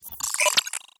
Computer Calculations 2.wav